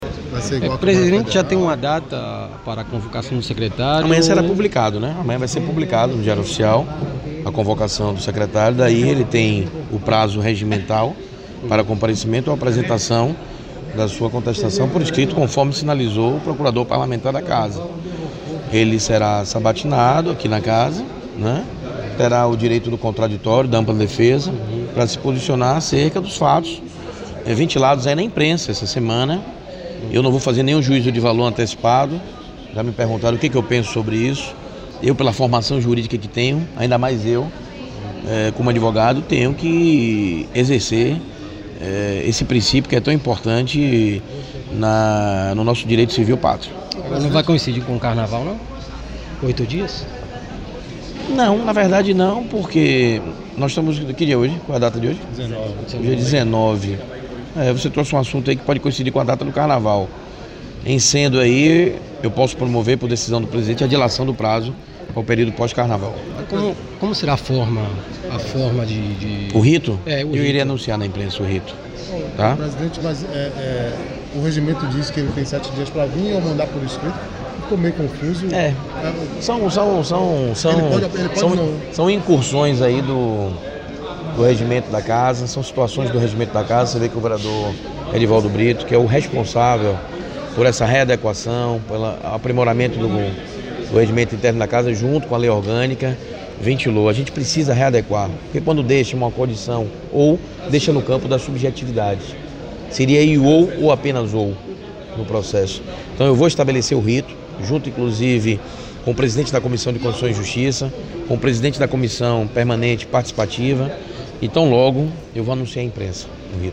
Em entrevista, o presidente da Câmara Municipal confirmou que a convocação do titular da SEMTEL, Alberto Pimentel, será publicada nesta quarta-feira (20), no Diário Oficial.